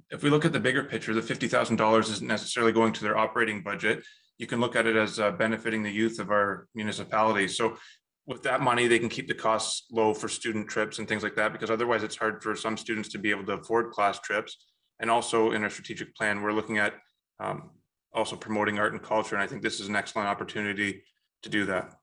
Councillor Michael Kotsovos interjected to speak about what the commitment that council made to the museum will mean to the community.